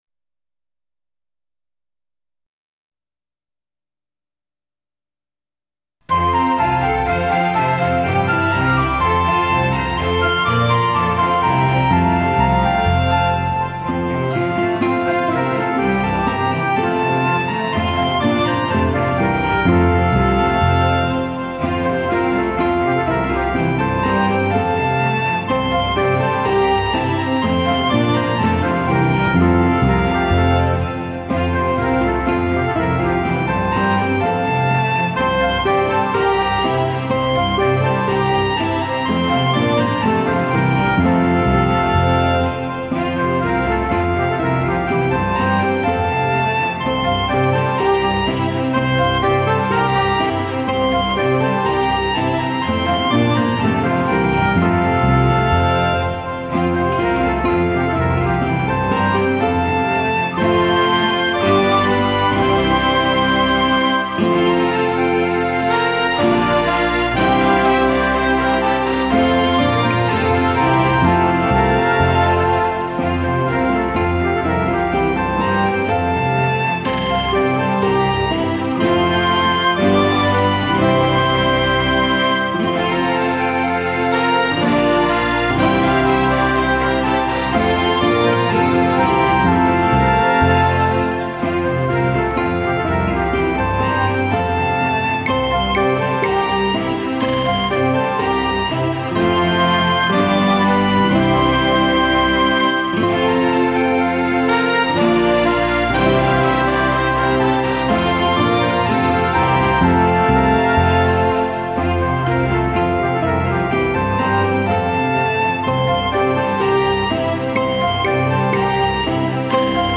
Traditional Christmas Song
The_Twelve_Days_of_Christmas_-_Karaoke.mp3